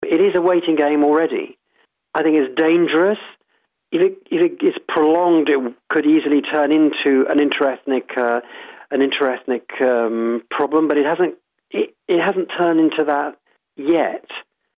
Џуда кој долго време го следи и пишува за Балканот, во интервју за Радио Слободна Европа вели дека Брисел може да помогне, но не може да го реши проблемот ако не помогнат македонските политичари и предупредува дека не треба да се дозволи политичката криза да прерасне во меѓуетнички конфликт.